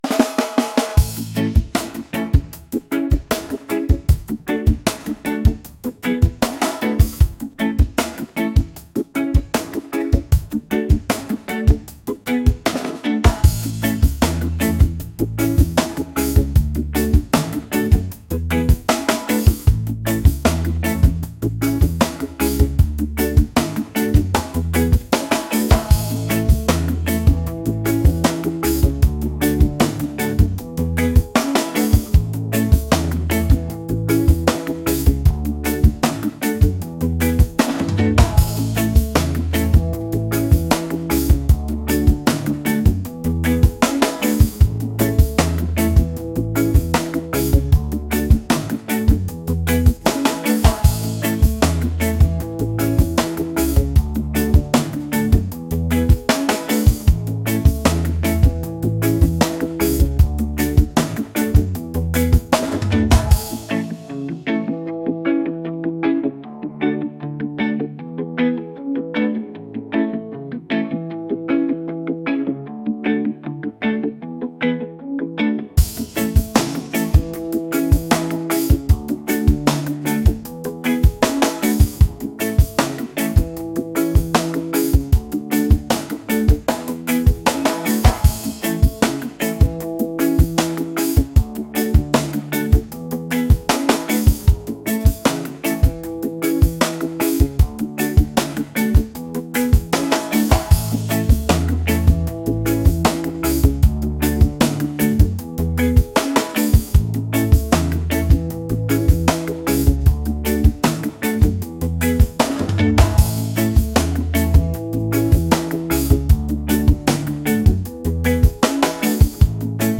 reggae | upbeat